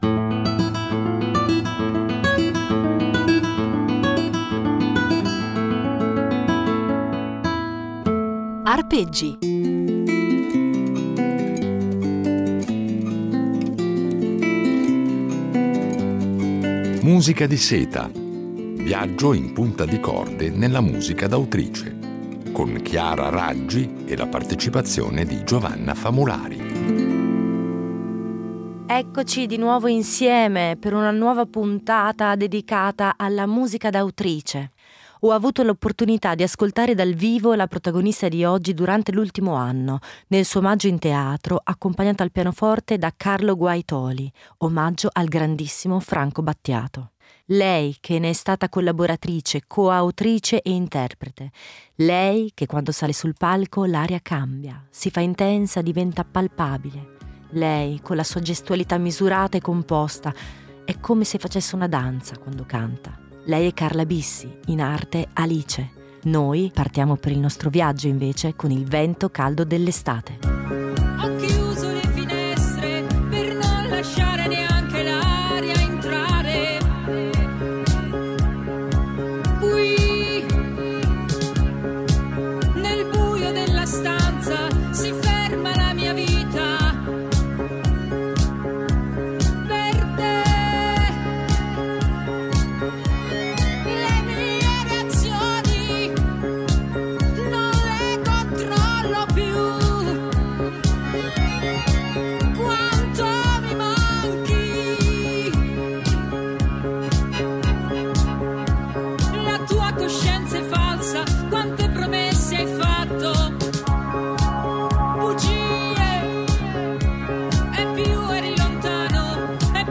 Una serie di Arpeggi impreziosita dalle riletture originali di un duo, ancora inedito